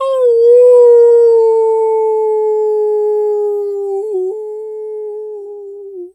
Animal_Impersonations
wolf_2_howl_long_06.wav